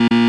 警告.wav